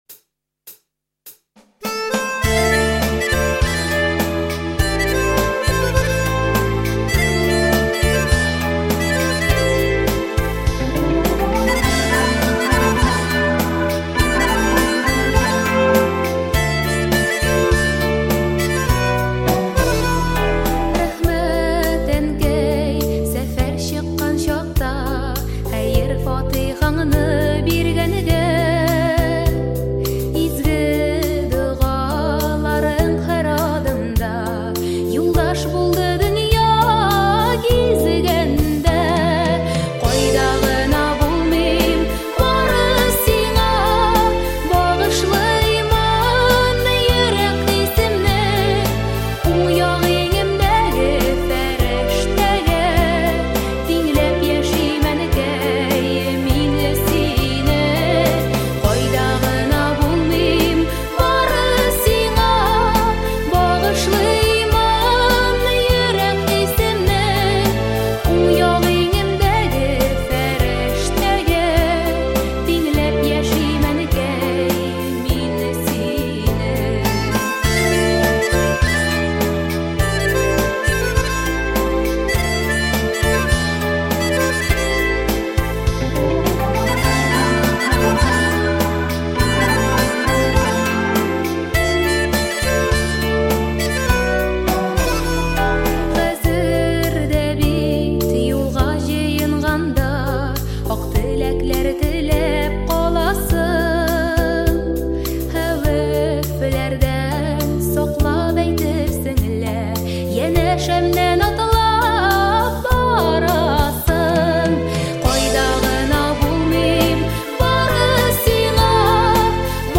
• Категория: Детские песни
татарские детские песни